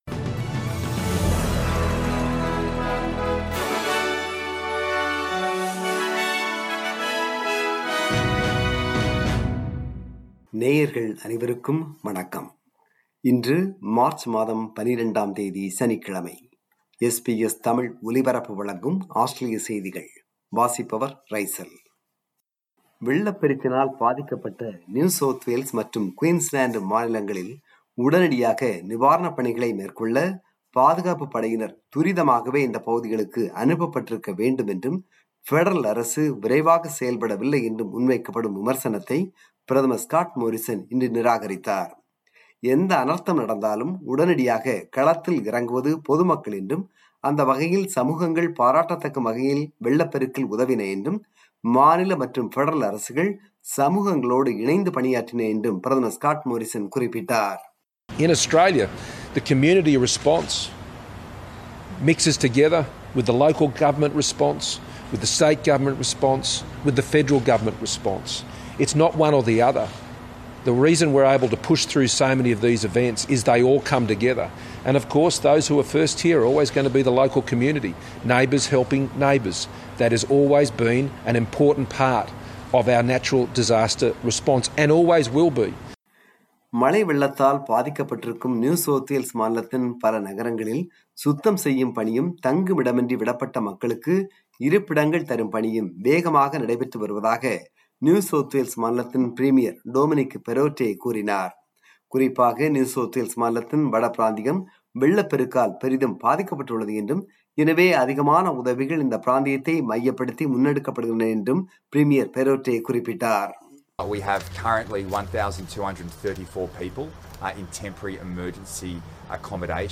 Australian News: 12 March 2022 – Saturday